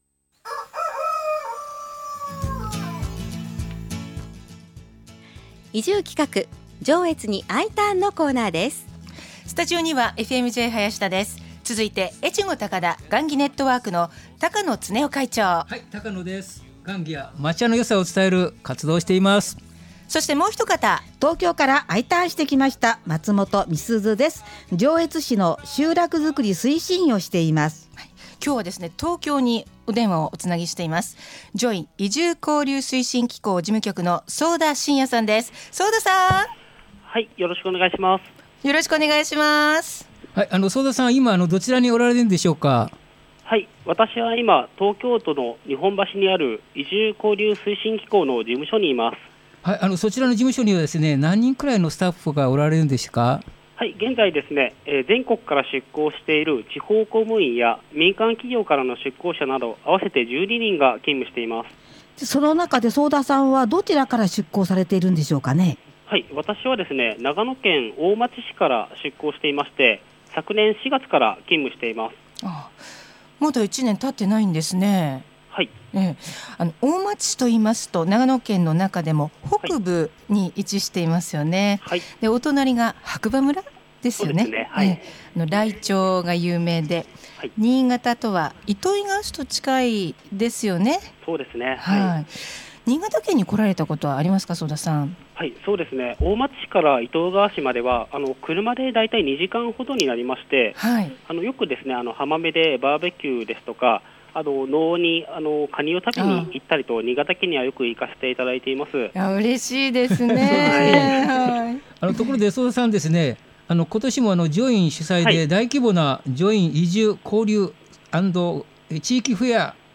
今回はFM-Jのスタジオから移住をお誘いするコーナーです。